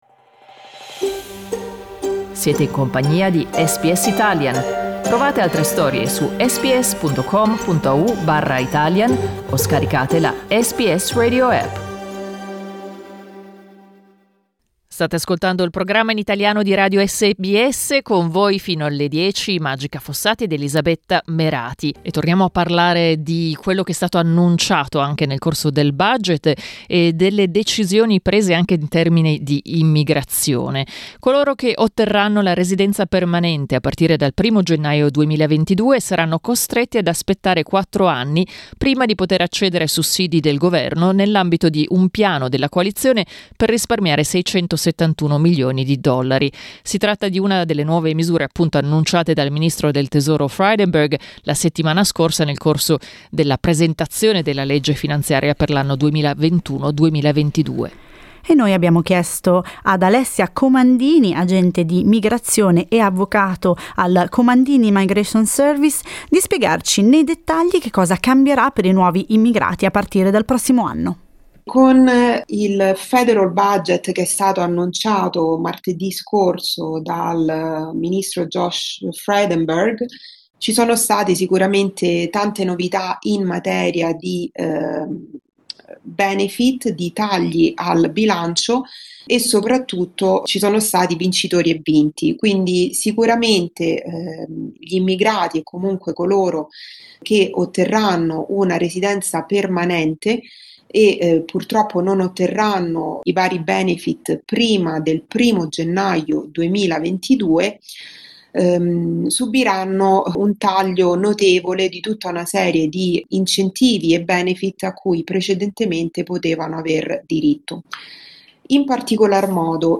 In un'intervista con SBS Italian